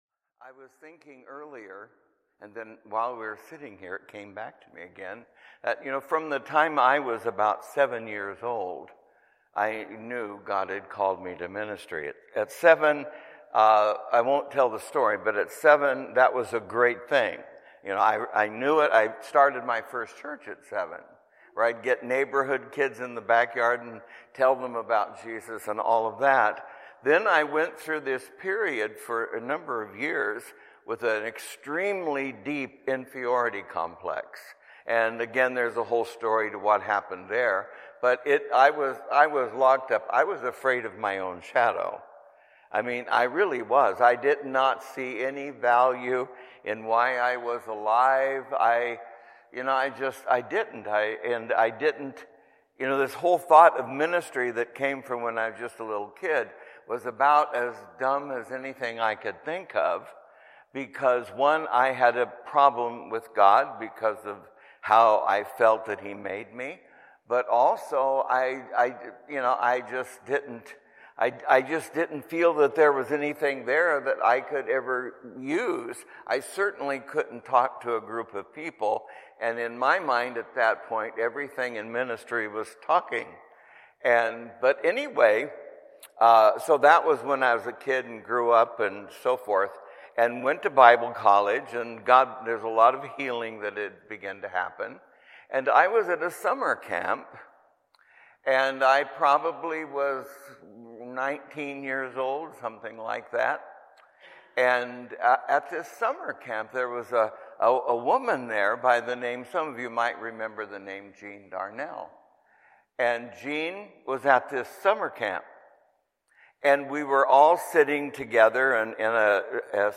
What do you have, Bassett Street Sermons